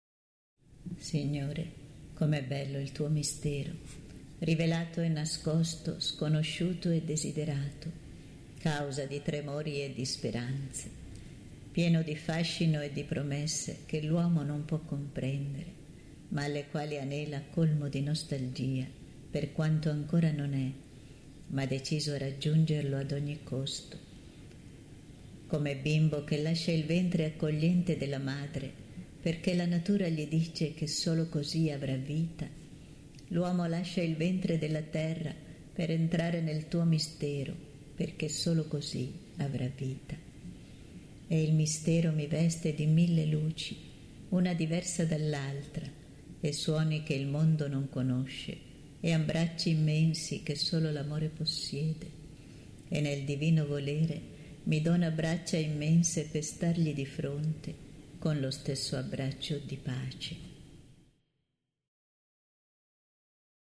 preghiera mp3 – Gesù canto mp3